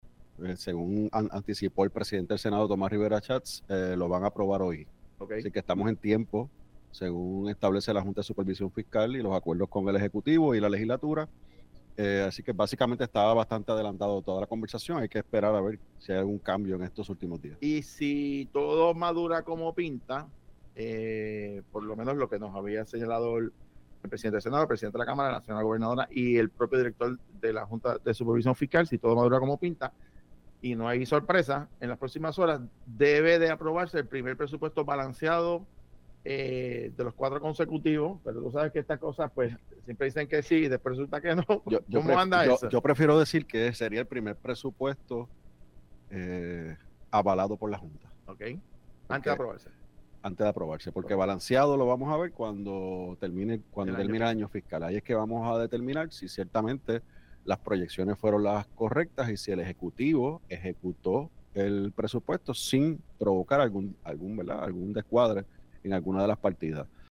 El representante Gabriel Rodríguez Aguiló informó en Los Colberg que el Senado de Puerto Rico anticipa aprobar el presupuesto del año fiscal 2026.